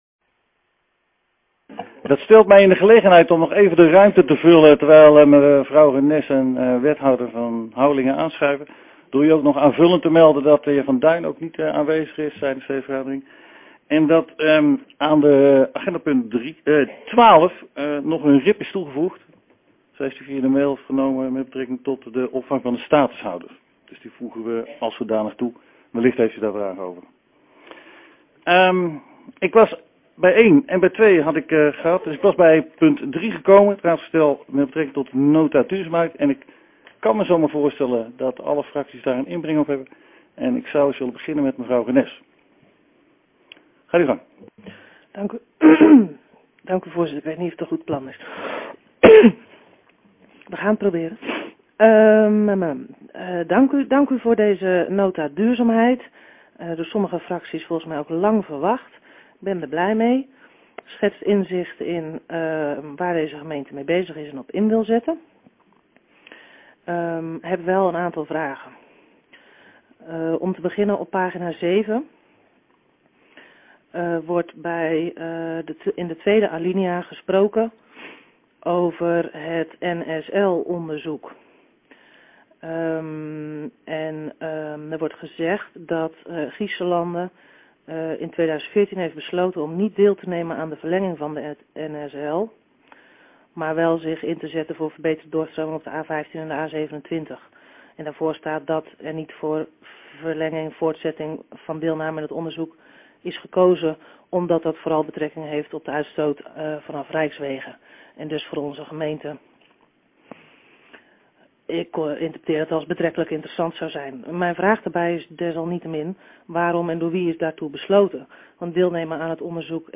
Hoornaar, gemeentehuis - raadzaal